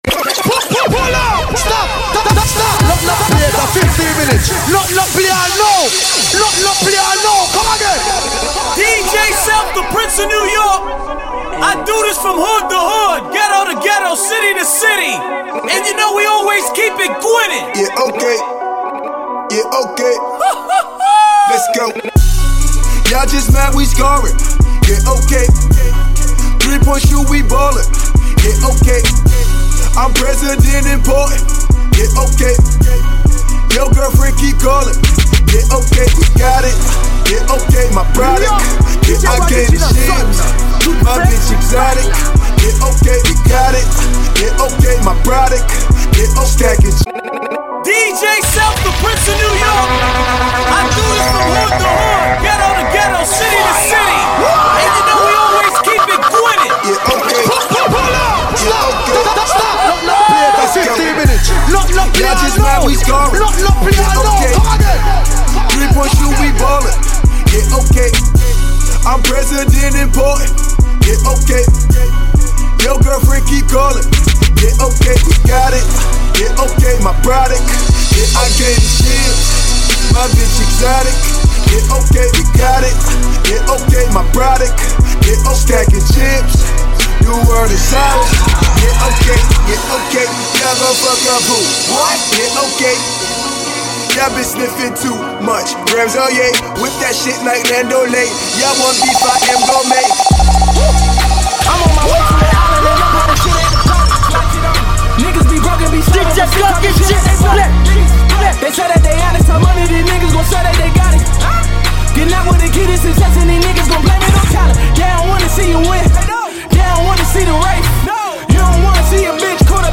Genre: Mix